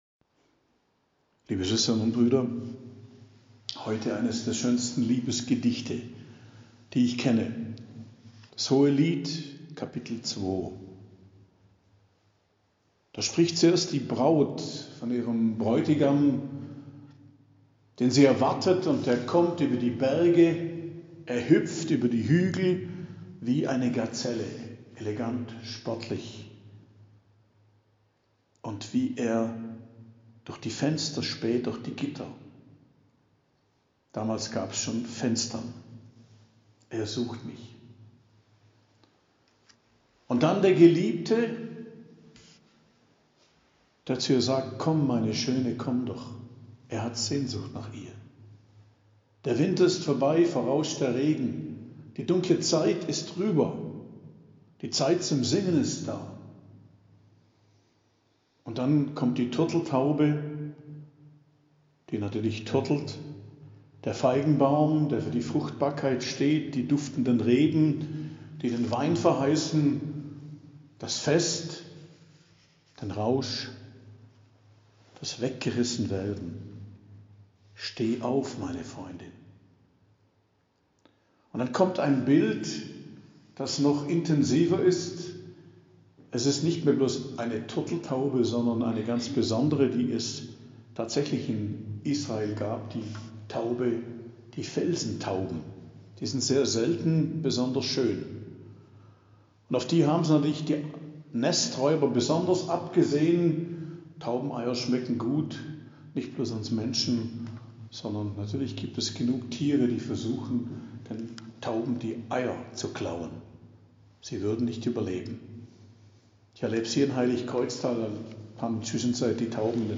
Predigt am Mittwoch der 4. Woche im Advent, 21.12.2022